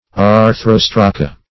Search Result for " arthrostraca" : The Collaborative International Dictionary of English v.0.48: Arthrostraca \Ar*thros"tra*ca\, n. pl.
arthrostraca.mp3